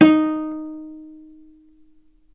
healing-soundscapes/Sound Banks/HSS_OP_Pack/Strings/cello/pizz/vc_pz-D#4-ff.AIF at 61d9fc336c23f962a4879a825ef13e8dd23a4d25
vc_pz-D#4-ff.AIF